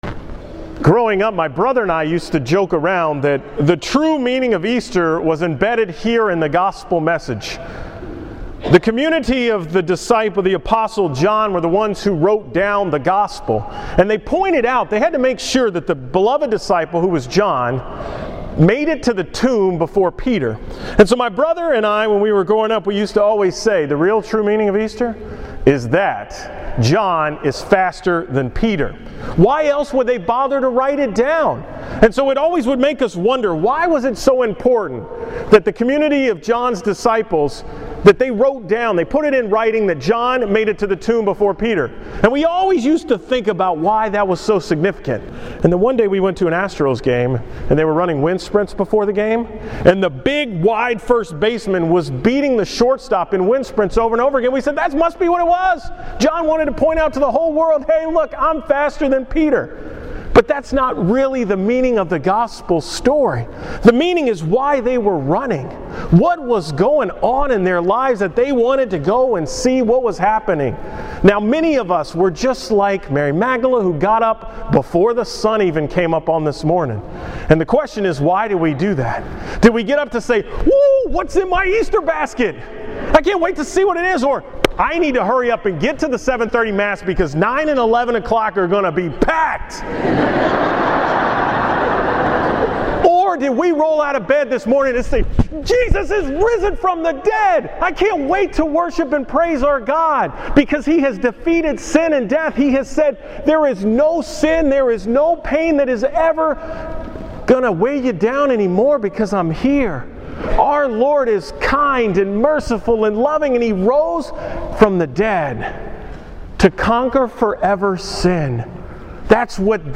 Homily on Easter morning at 7:30 am Mass